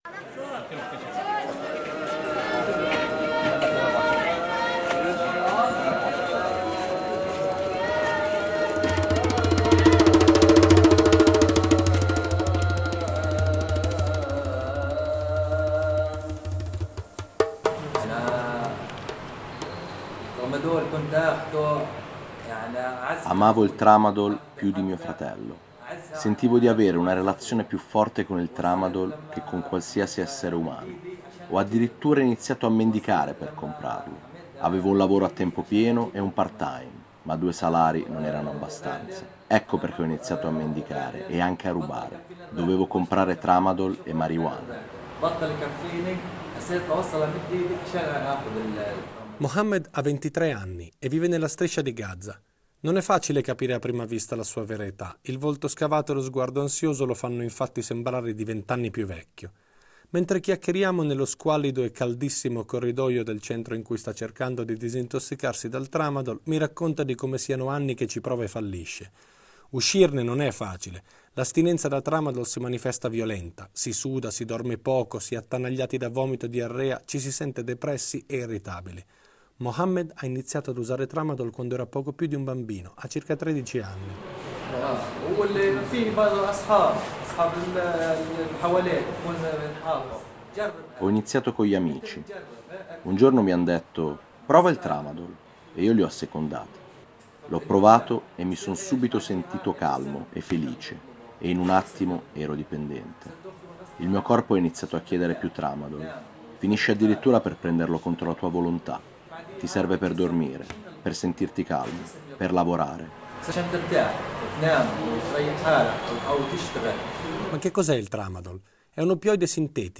Questo Laser ci porta a Gaza dove incontreremo tossicodipendenti, avvocati e medici per capire cosa vuol dire essere intrappolati dal Tramadol, da dove arriva, chi ci guadagna e a che punto è la guerra contro l’abuso di un farmaco che si sta rivelando un problema sempre più globale.